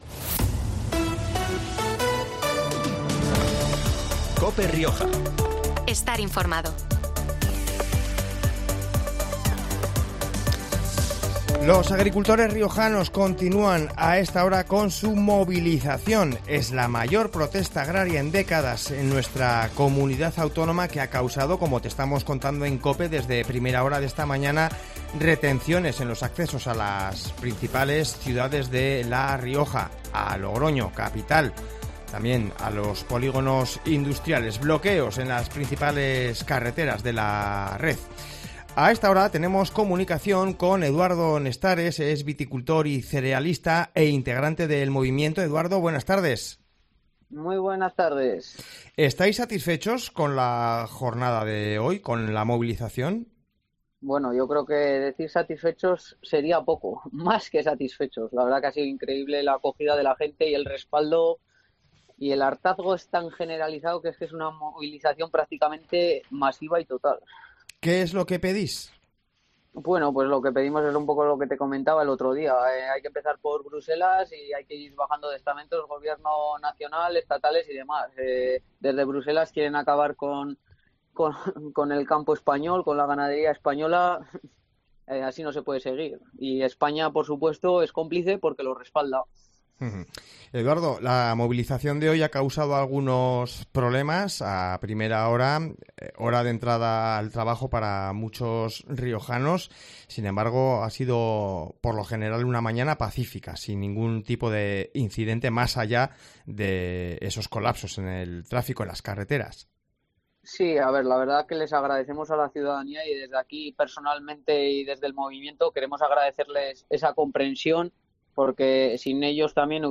Los agricultores riojanos continuarán con las movilizaciones en los próximos días, así los han confirmado en los micrófonos de COPE Rioja: